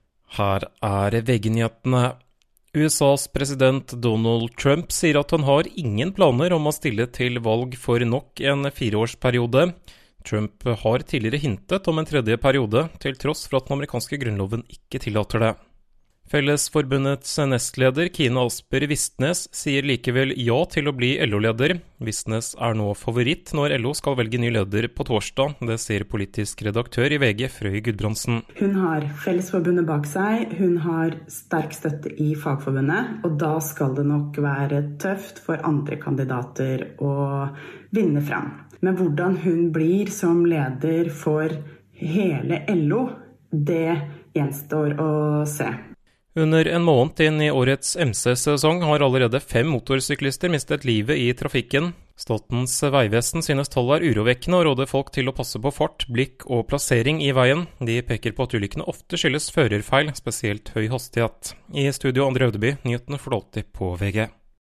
1 Siste nytt fra VG 1:07 Play Pause 2h ago 1:07 Play Pause Na później Na później Listy Polub Polubione 1:07 Hold deg oppdatert med ferske nyhetsoppdateringer på lyd fra VG. Nyhetene leveres av Bauer Media/Radio Norge for VG.